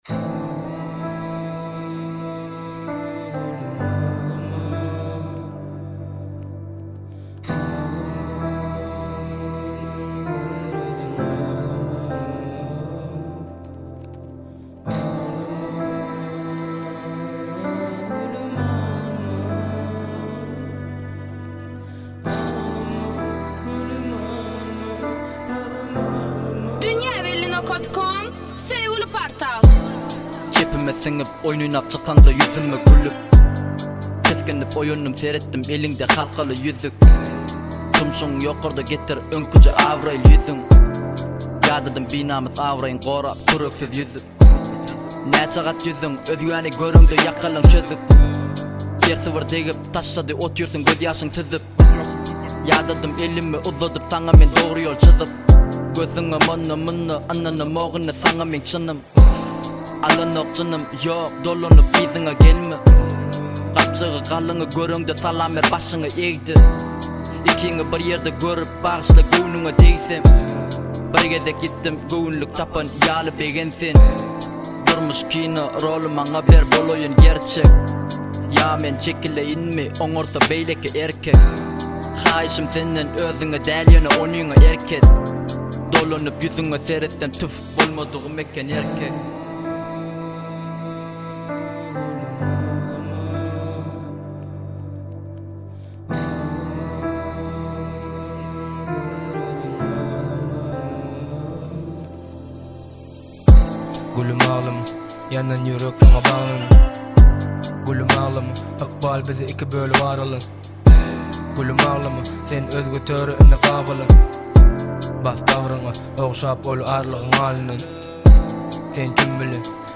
Bölüm: Türkmen Aýdymlar / Rep